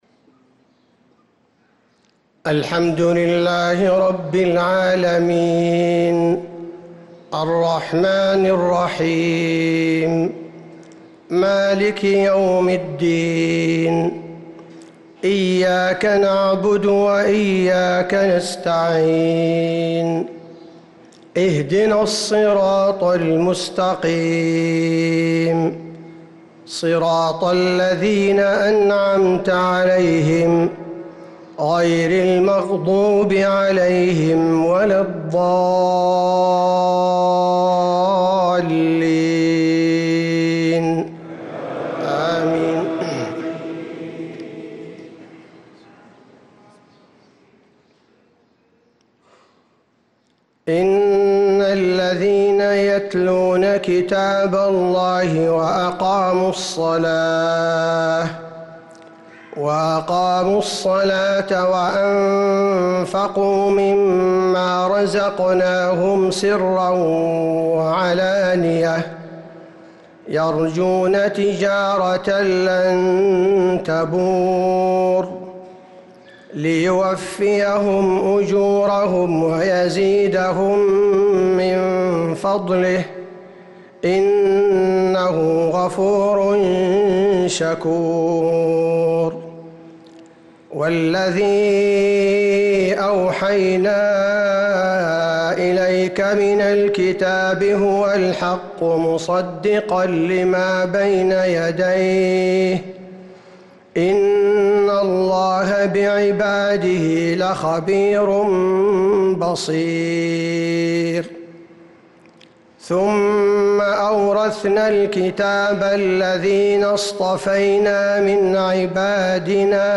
صلاة الفجر للقارئ عبدالباري الثبيتي 27 رمضان 1445 هـ
تِلَاوَات الْحَرَمَيْن .